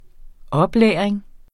Udtale [ -ˌlεˀʁeŋ ]